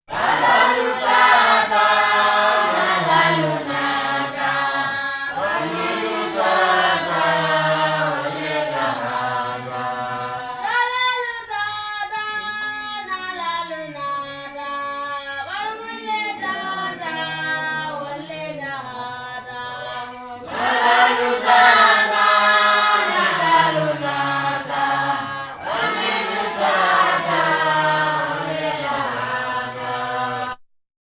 VOC 01.AIF.wav